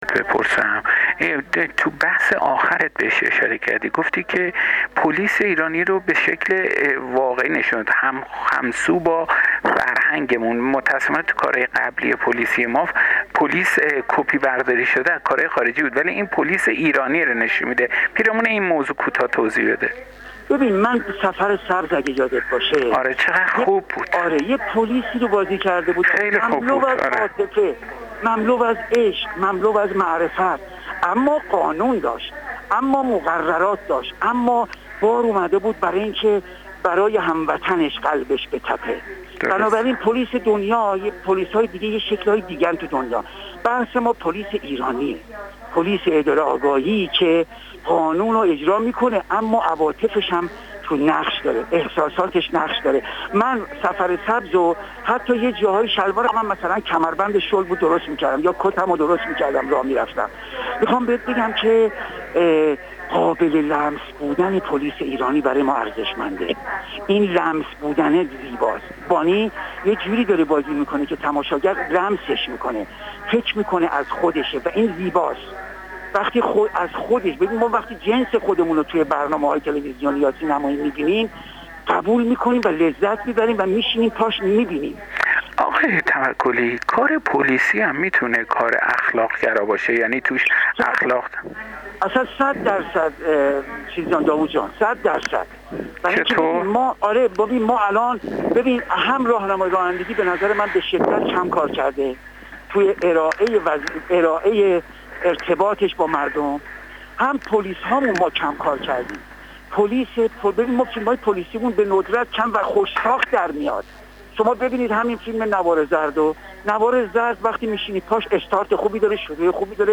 رضا توکلی، بازیگر سینما و تلویزیون در گفتگو با خبرگزاری حوزه، پیرامون حضورش در سریال «نوار زرد» اظهار کرد: این مجموعه توانسته به خوبی تصویرگر پلیس ایرانی باشد.